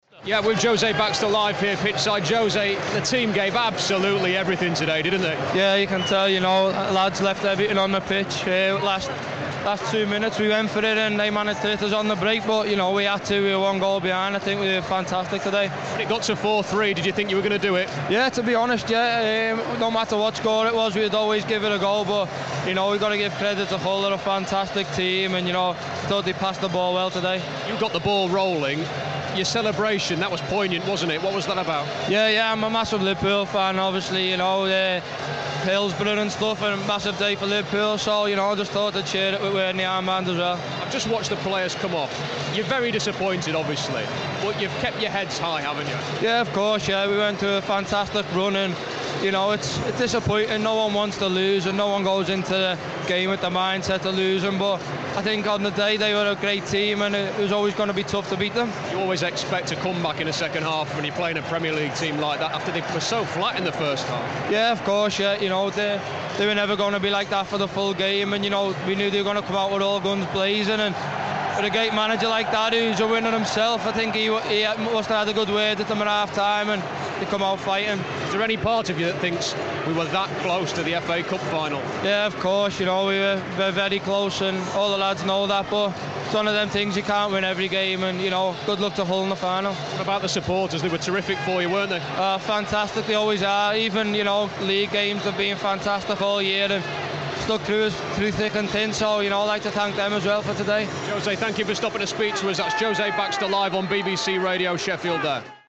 INTERVIEW
on the pitch after 5-3 Semi Final defeat